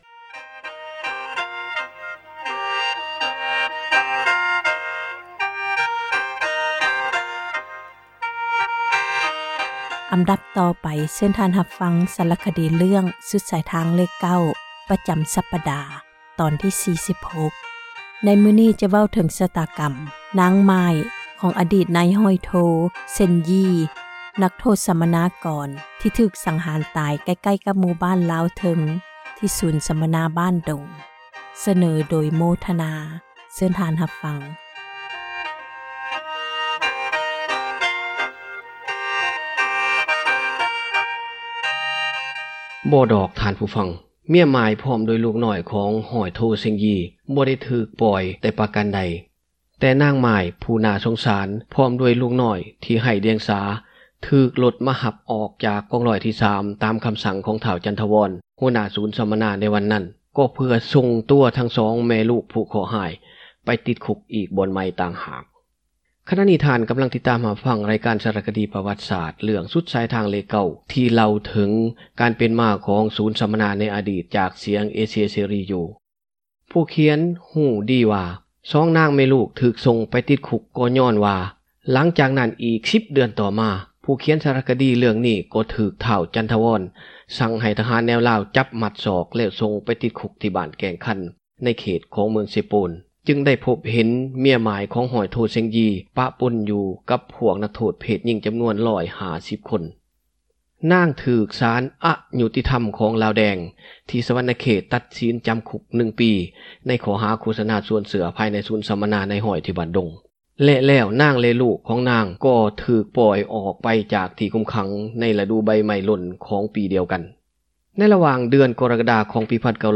ສາຣະຄະດີ ເຣື້ອງ ສຸດສາຍທາງເລຂ 9 ຕອນທີ 46